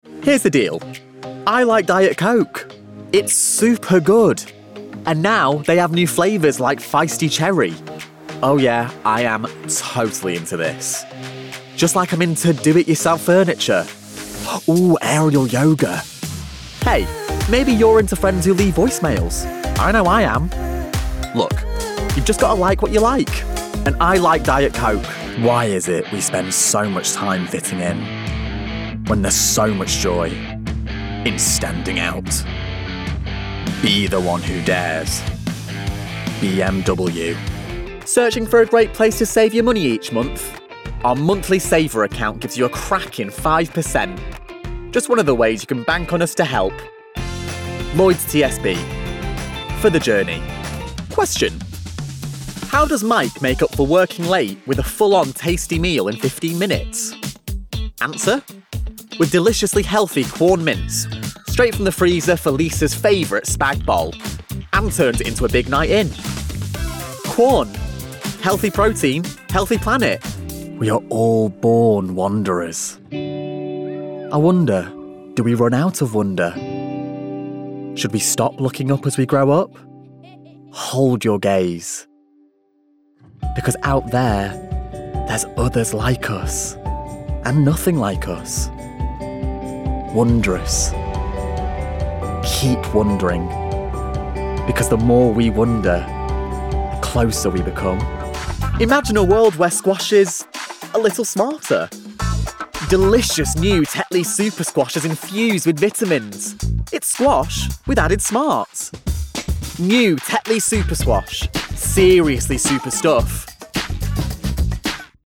Voice Reel
Commercial reel